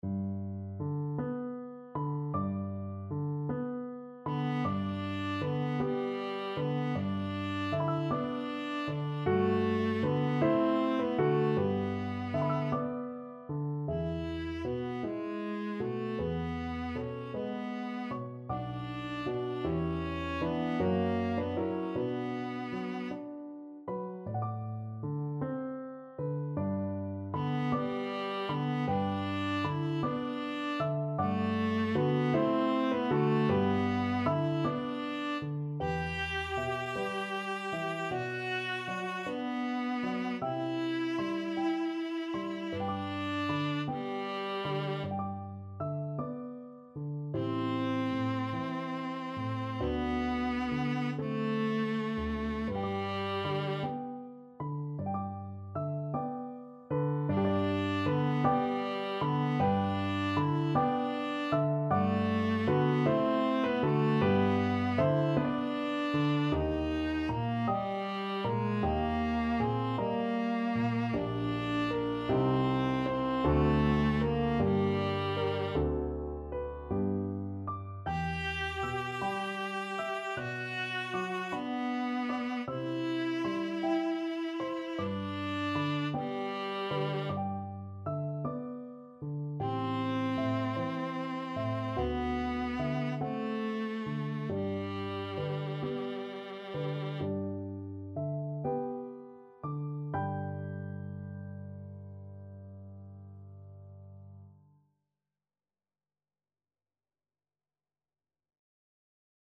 ~. = 52 Allegretto
6/8 (View more 6/8 Music)
Classical (View more Classical Viola Music)